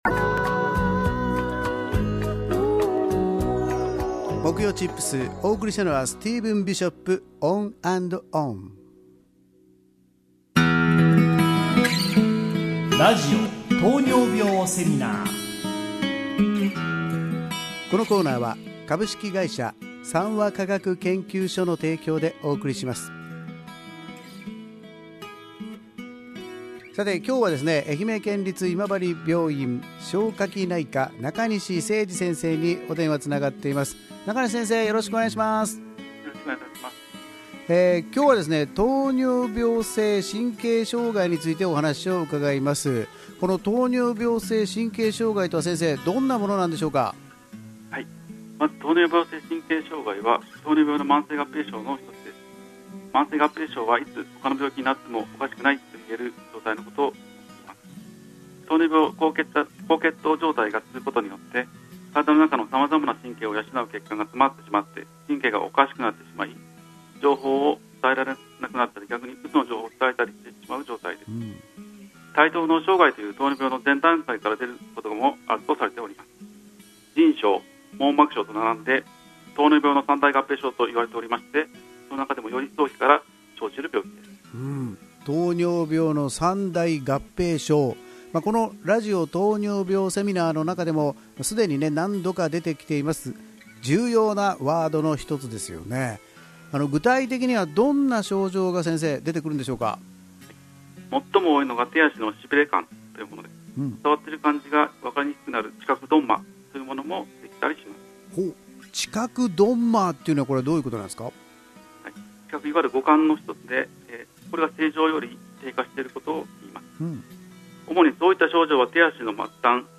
医師